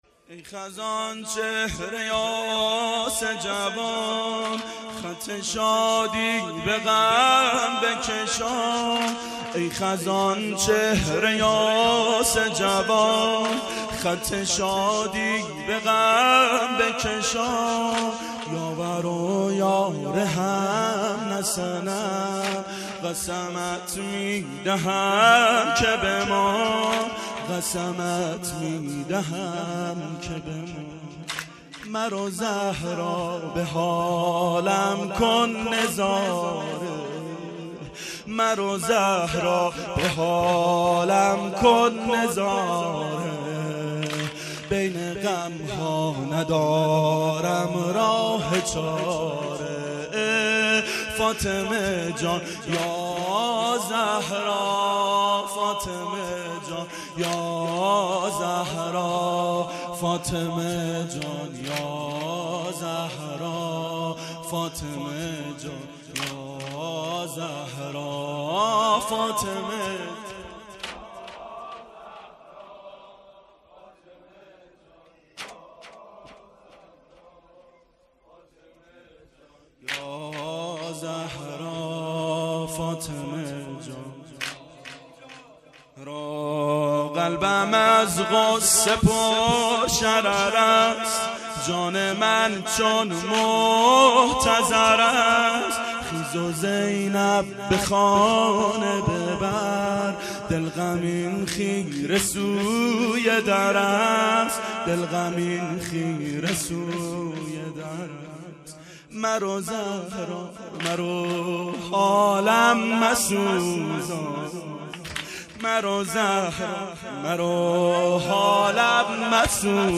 ظهر شهادت حضرت زهرا سلام الله علیها1392 هیئت شیفتگان حضرت رقیه س